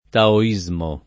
taoismo [ tao &@ mo ]